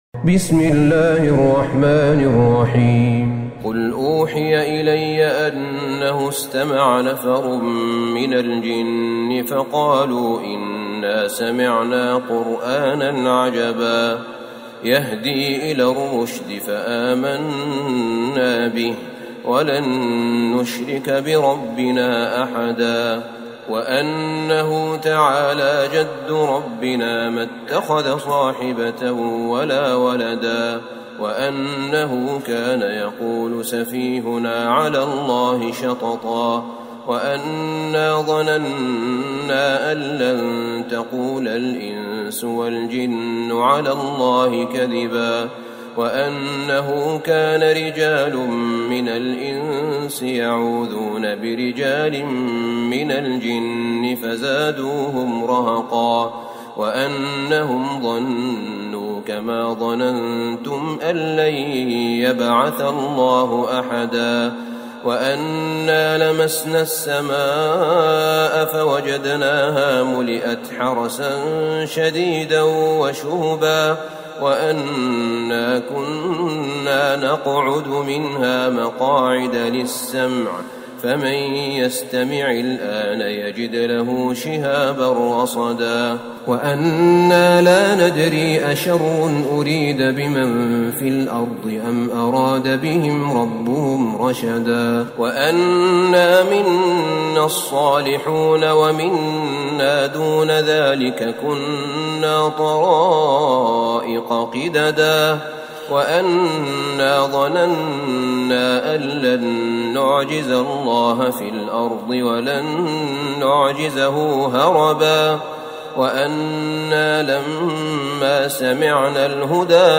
سورة الجن Surat Al-Jinn > مصحف الشيخ أحمد بن طالب بن حميد من الحرم النبوي > المصحف - تلاوات الحرمين